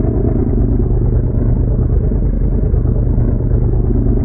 SUBMARINE_Engine_01_loop_mono.wav